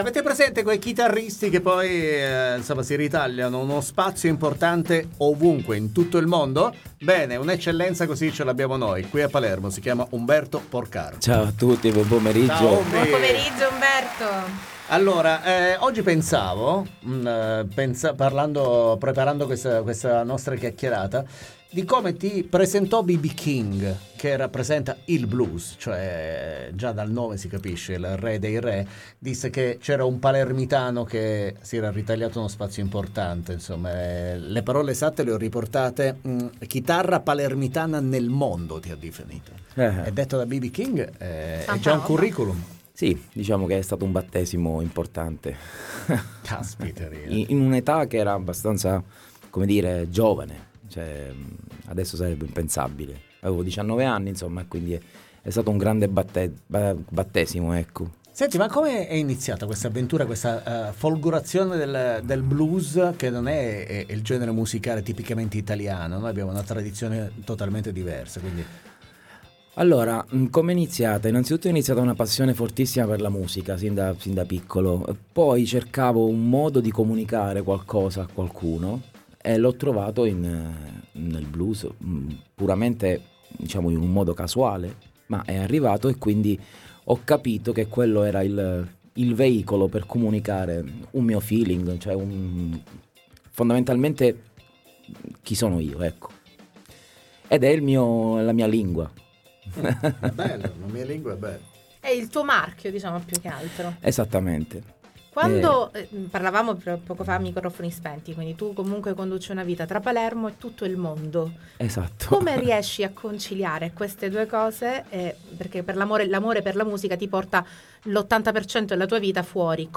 DELL’ITALIA ALL’INTERNATIONAL BLUES CHALLENGE Interviste Tandem 28/10/2024 12:00:00 AM / TANDEM Condividi: